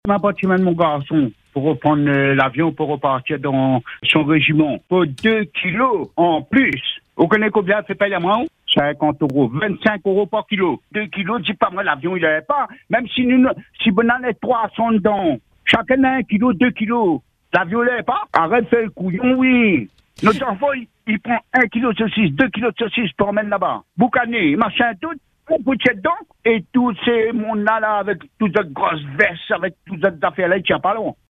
Il a tenu à témoigner sur notre antenne.